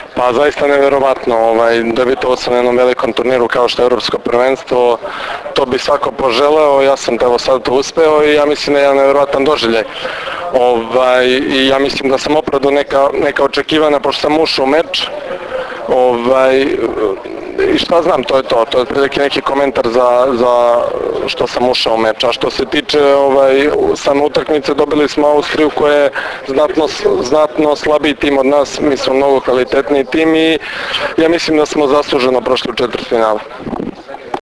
IZJAVA UROŠA KOVAČEVIĆA